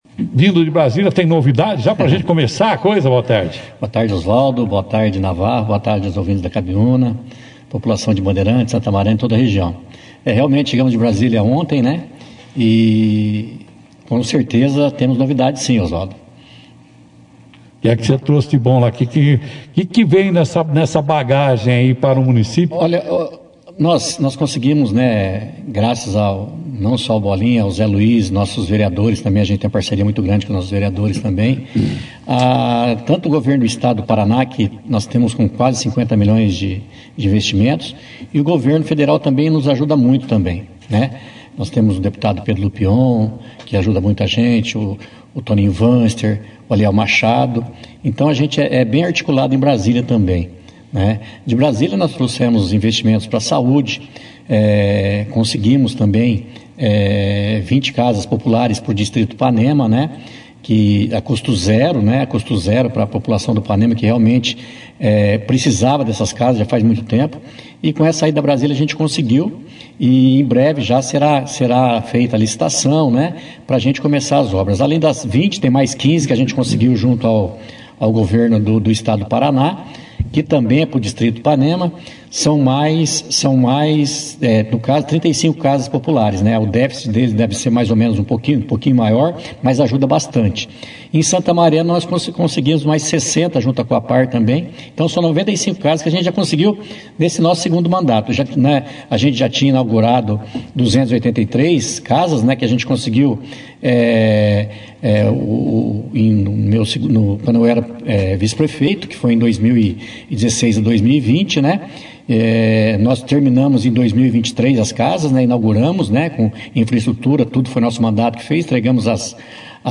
O prefeito de Santa Mariana, Marcelo Bolinha, participou neste sábado (28) do Jornal Operação Cidade e apresentou um balanço das ações da administração municipal, destacando investimentos em diversas áreas.
prefeito-Santa-Mariana-Bolinha-no-Operacao-Cidade.mp3